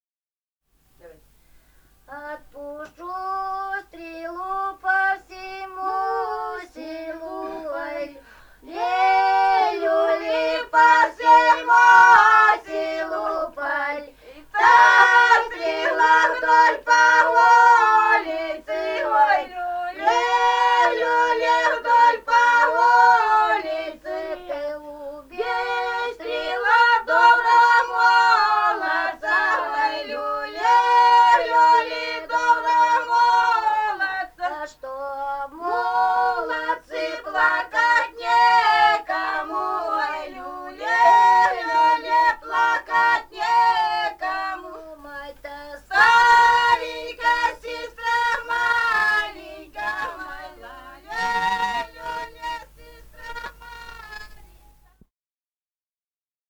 полевые материалы
Алтайский край, с. Тигирек Краснощёковского района, 1967 г. И1002-10б